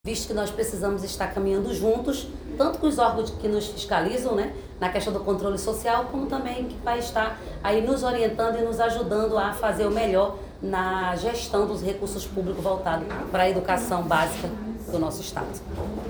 Sonora_Educacao_Secretaria-Arlete-Mendonca-.mp3